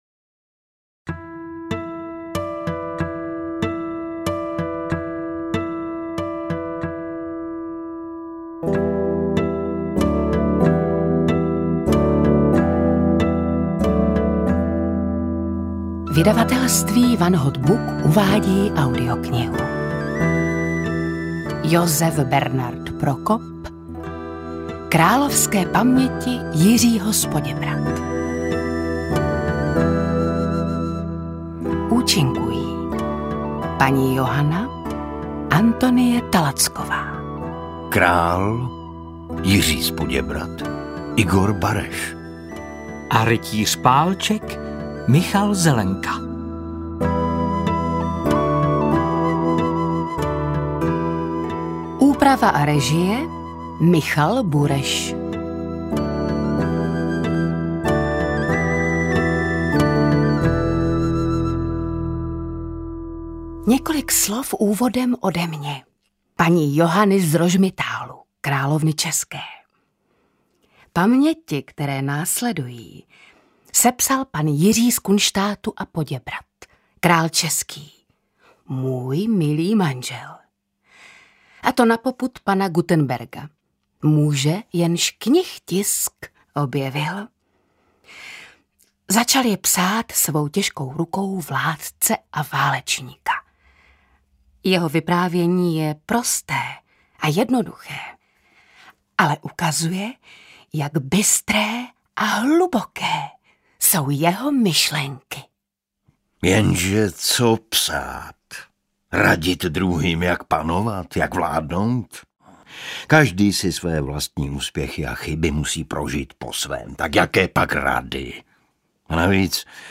AudioKniha ke stažení, 30 x mp3, délka 13 hod. 41 min., velikost 751,0 MB, česky